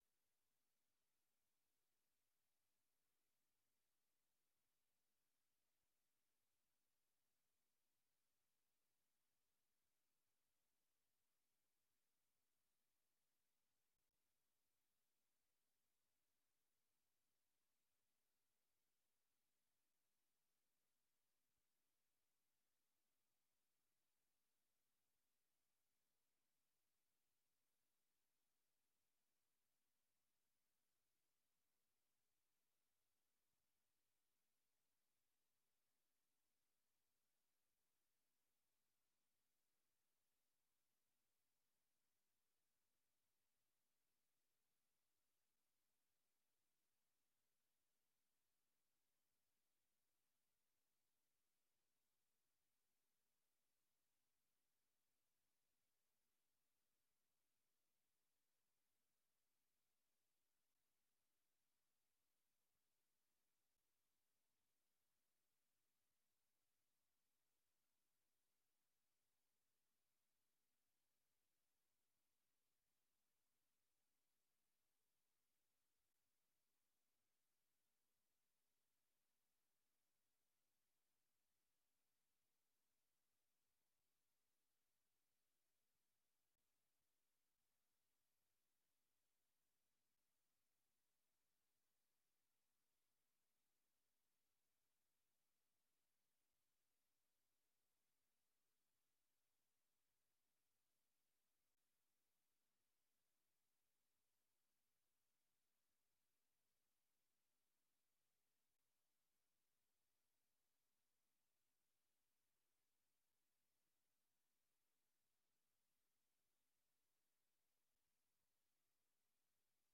Raadsvergadering 09 juni 2022 19:30:00, Gemeente Dronten
Locatie: Raadzaal